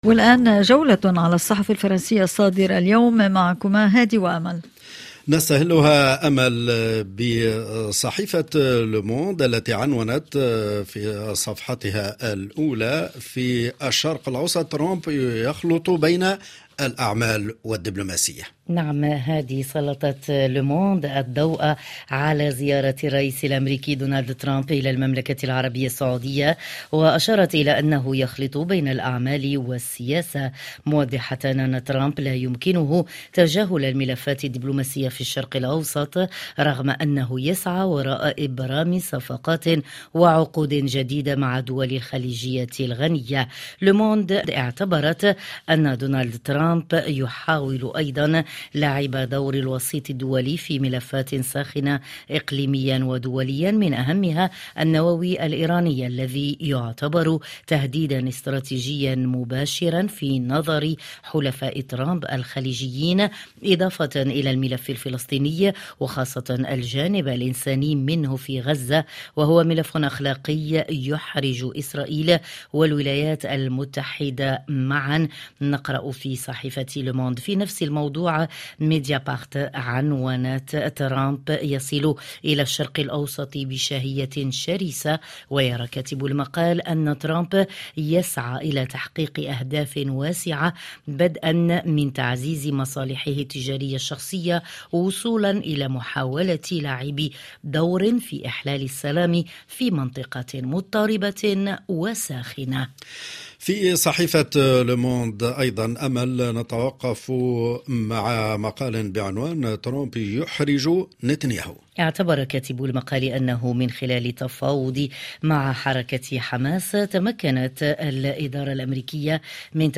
ما لم تقرؤوه في صحف الصباح تستمعون إليه عبر أثير "مونت كارلو الدولية" في عرض يومي صباحي لأهم التعليقات والتحليلات لكل قضايا الساعة في فرنسا والعالم العربي والعالم وحازت على اهتمام الصحف الفرنسية.